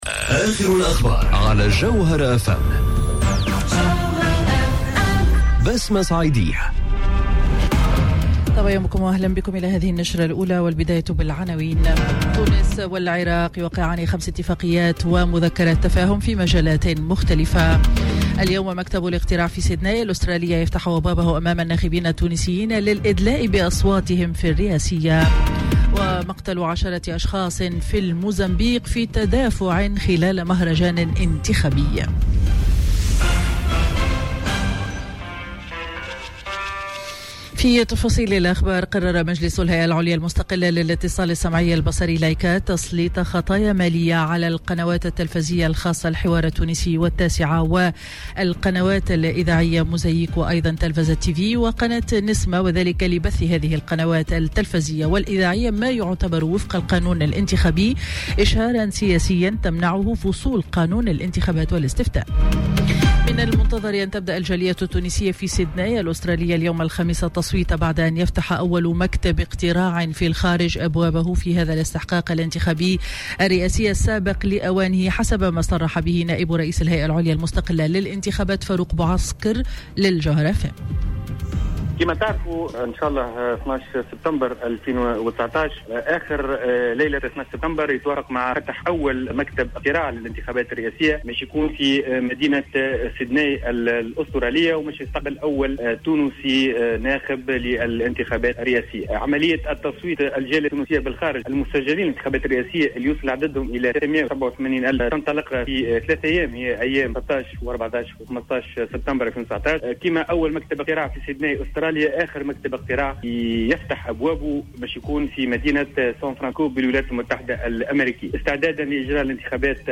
نشرة أخبار السابعة صباحا ليوم الخميس 12 سبتمبر 2019